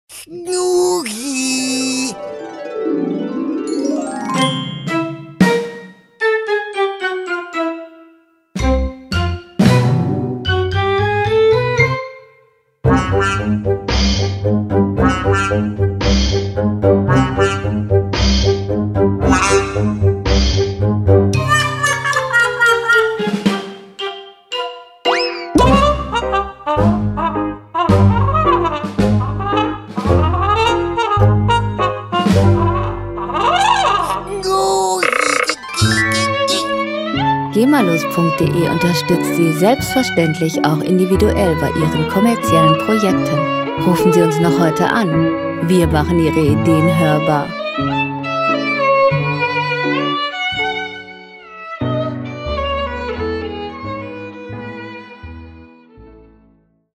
• Cartoon Musik